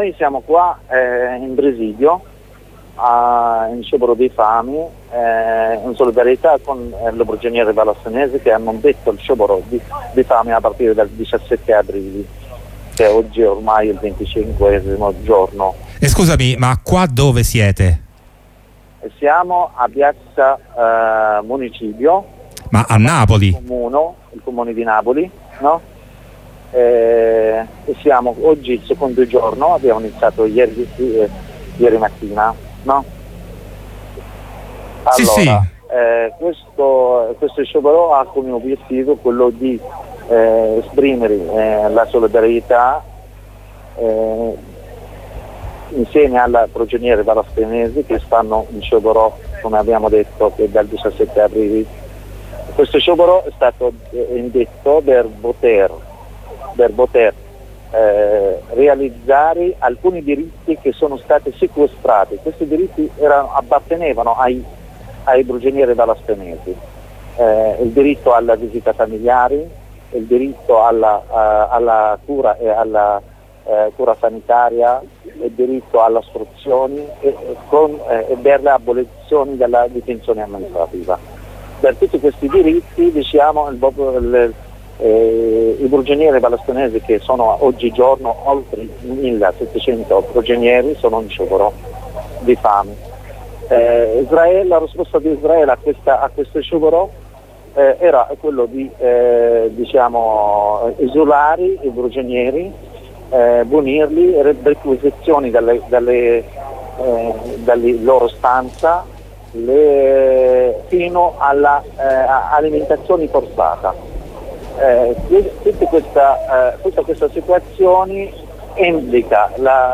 Corrispondenza dal presidio a Napoli sotto il comune in piazza Plebiscito della comunità palestinese in sciopero della fame in solidarietà con i più di 1700 prigionieri nella carceri israeliane che rifiutano il cibo e chiedono almeno che siano applicati i diritti elementari che gli spetterebbero .